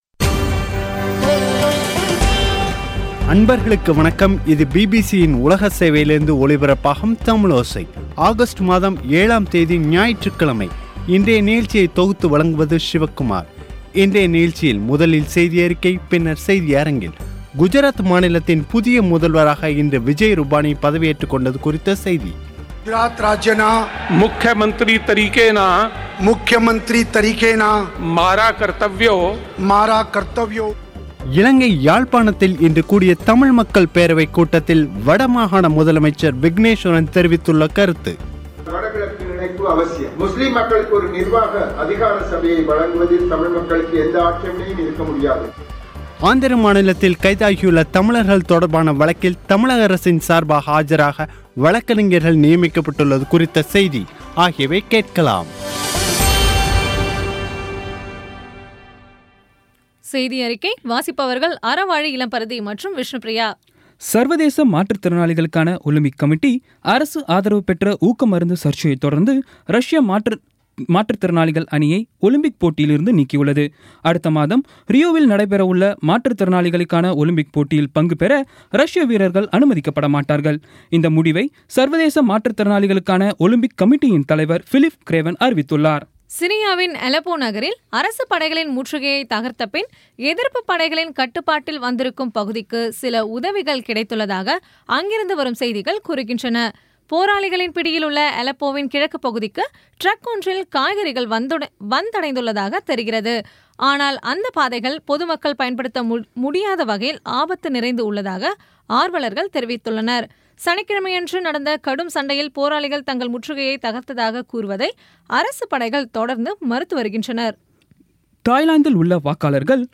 இன்றைய நிகழ்ச்சியில் முதலில் செய்தியறிக்கை, பின்னர் செய்தியரங்கில்